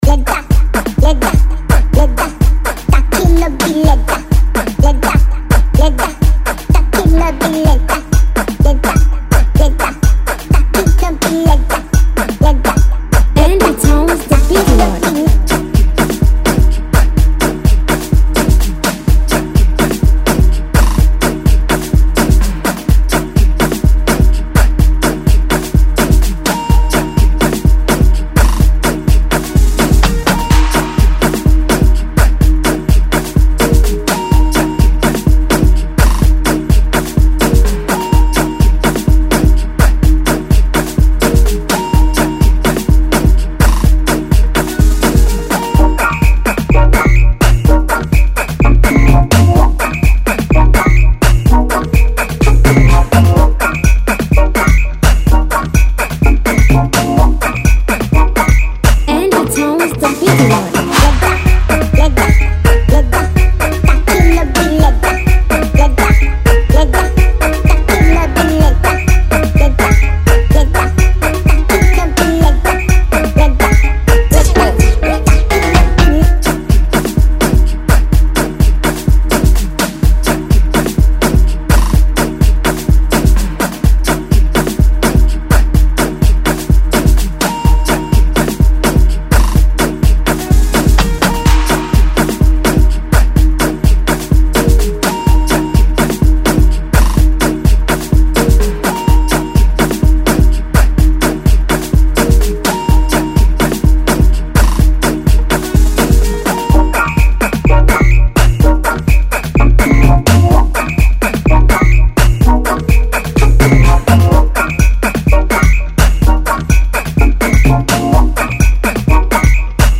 Freebeats